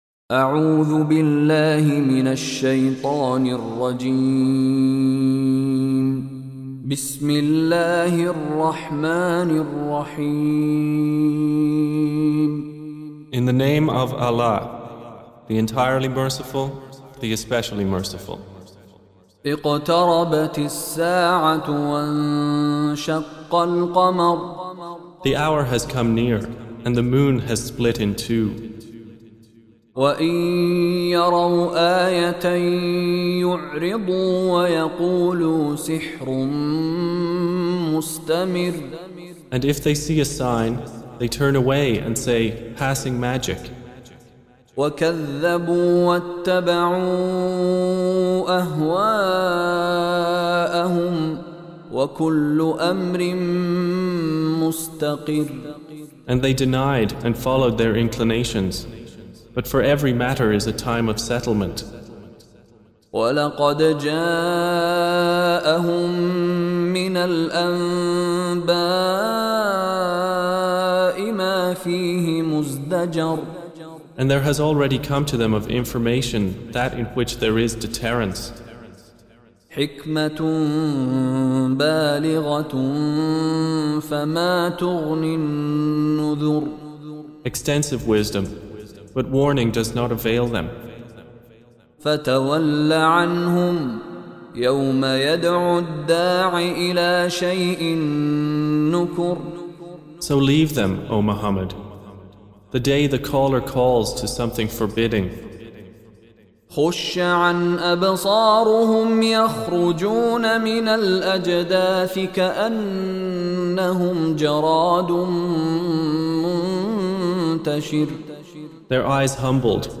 Surah Repeating تكرار السورة Download Surah حمّل السورة Reciting Mutarjamah Translation Audio for 54. Surah Al-Qamar سورة القمر N.B *Surah Includes Al-Basmalah Reciters Sequents تتابع التلاوات Reciters Repeats تكرار التلاوات